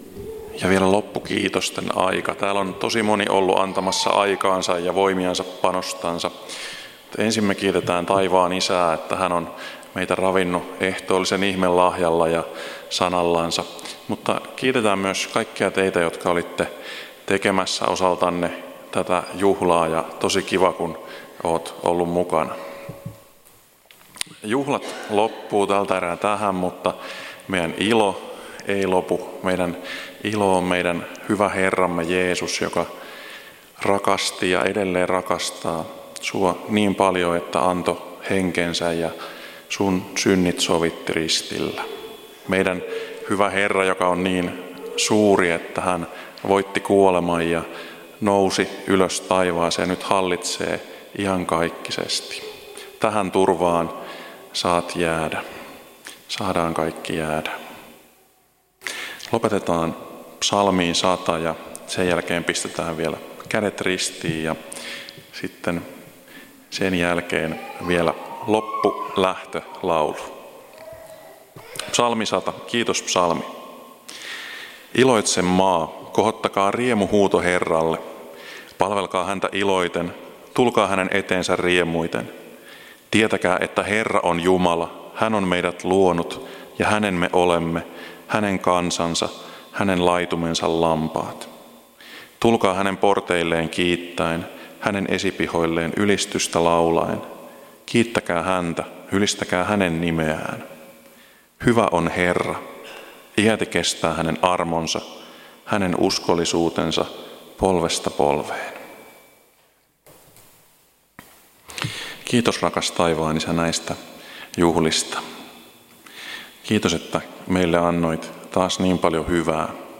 Loppuhartaus
Kokoelmat: Tampereen evankeliumijuhlat 2018